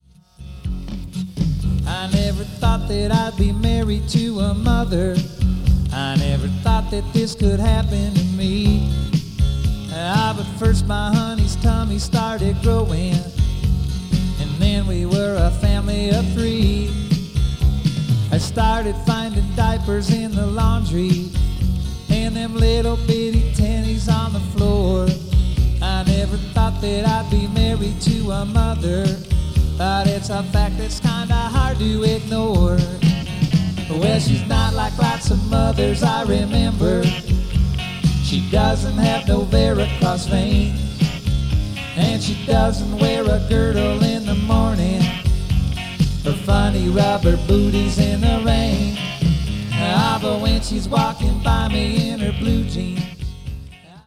on an old Teac 4-track, reel-to-reel recorder.